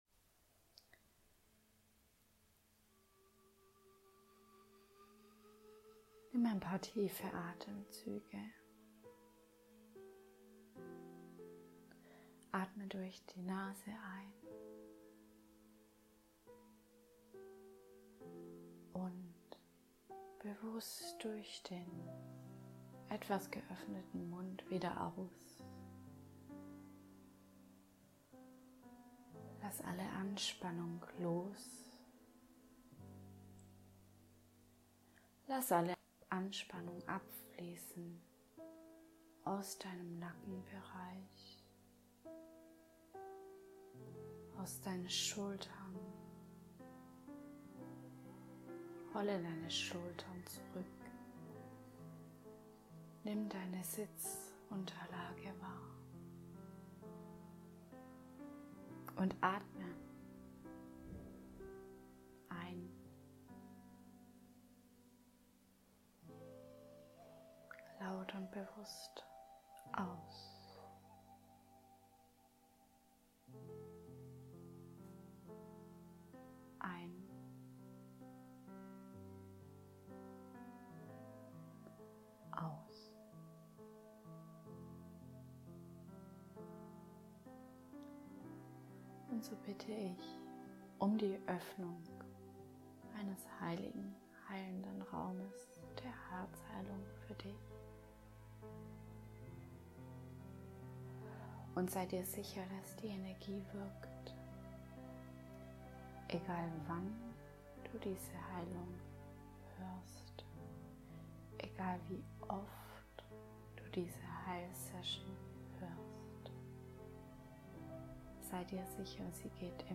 Produktseite Herzmeditation 0€ - Entrepreneurin by Design